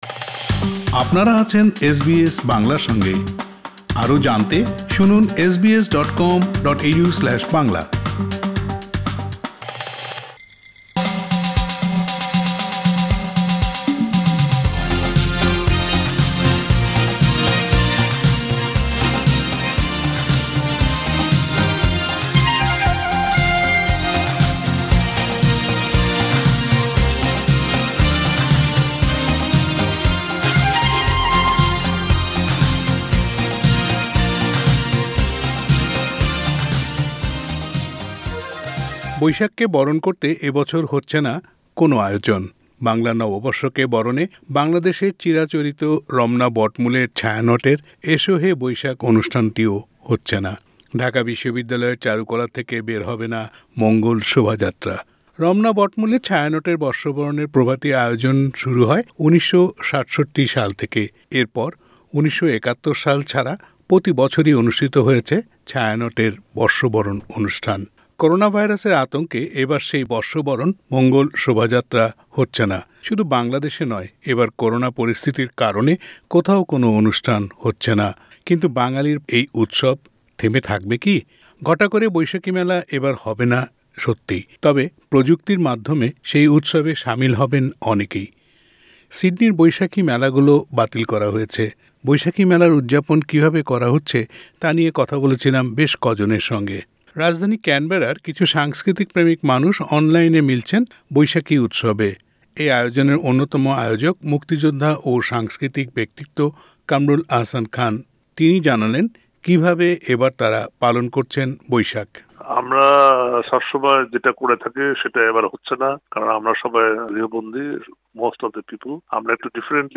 সিডনির বৈশাখী মেলা গুলো বাতিল করা হয়েছে। কিভাবে এবারের বৈশাখী মেলার উৎসব হবে হচ্ছে তা জানতে এস বি এস বাংলা কথা বলেছে বেশ কজনের সঙ্গে। সাক্ষাৎকারগুলো শুনতে উপরের অডিও প্লেয়ারের লিংকটিতে ক্লিক করুন।